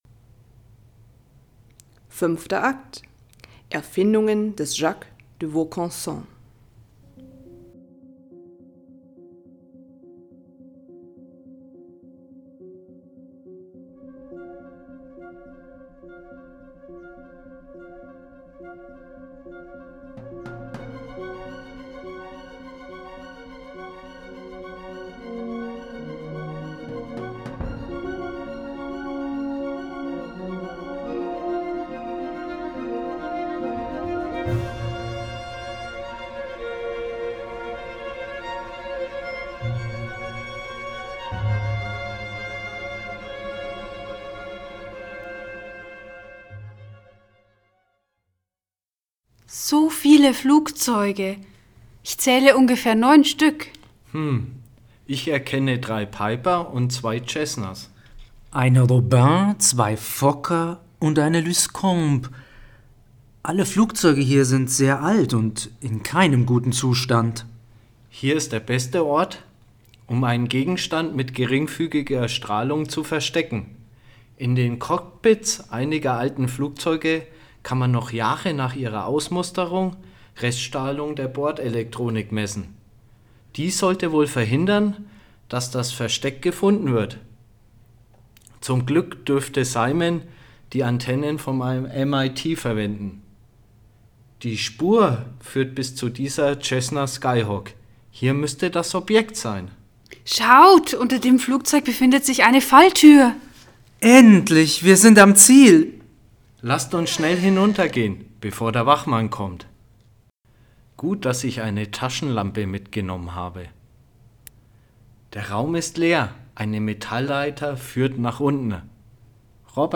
Das Drehbuch und die Sprachaufnahmen sind in Eigenproduktion entstanden, die Geräusche und Musikstücke wurden sorgfältig und nach bestem Wissen und Gewissen im Internet recherchiert und in das Hörspiel aufgenommen.